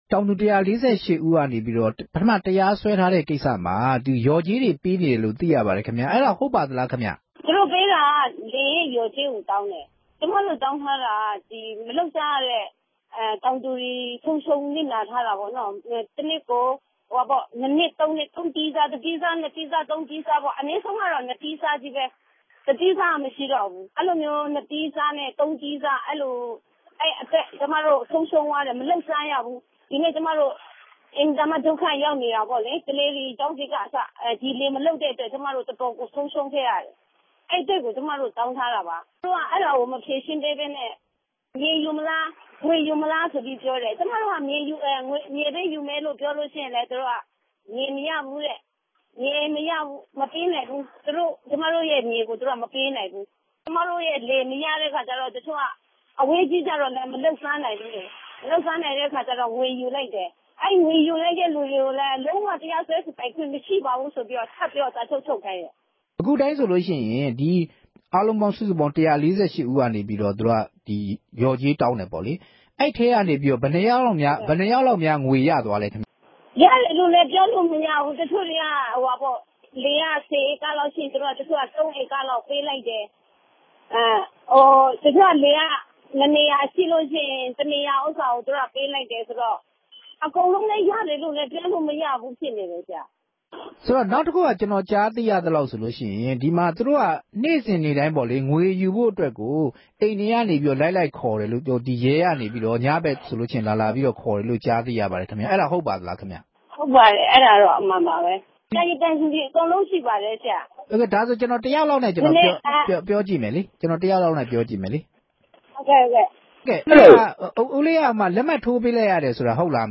ဆက်သွယ်မေးမြန်းချက်။